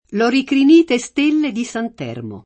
sant’Elmo [Sant $lmo] o sant’Ermo [Sant $rmo] n. pr. m. — propr., alteraz., secondo la forma sp.